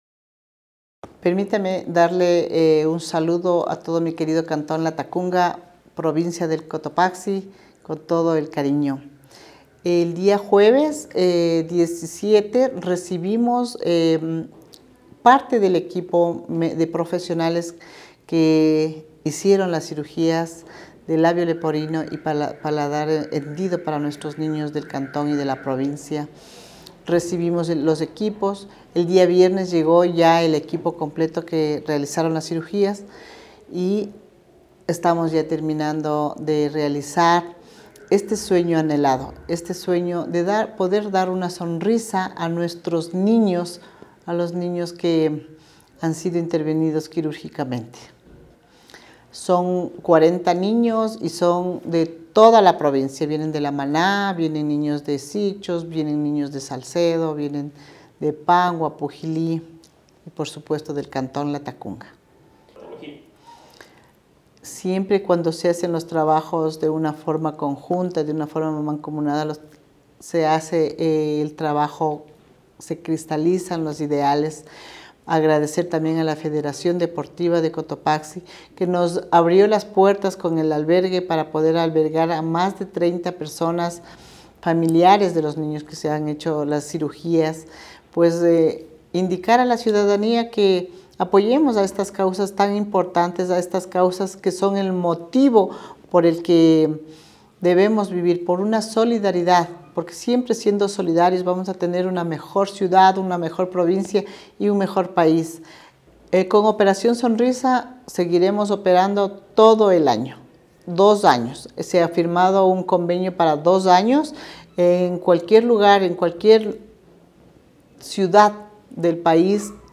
INTERVENCIÓN: